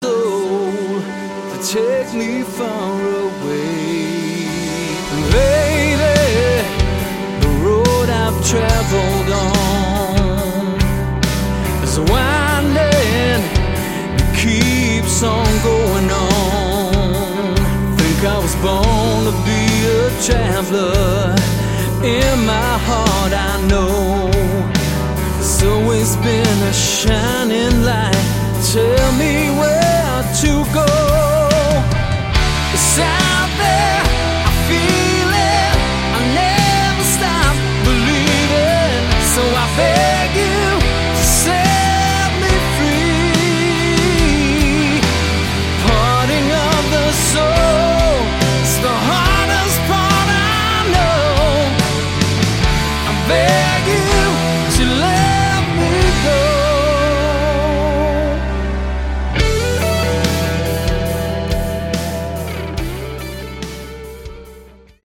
Category: AOR
bass guitar, guitar, vocals
drums, keyboards
lead guitar